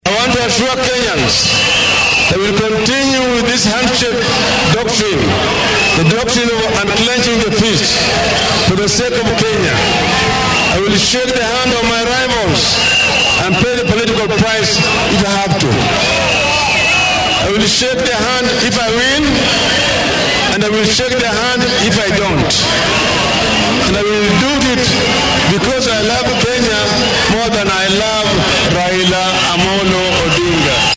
Xillin uu shlay isku soo bixisi ugu dambeeyay ku qabtay garoonka cayaaraha ee Kasarani ee magaalada Nairobi, ayuu sheegay inuu waligiis raacay nidaamka gacan qaadka.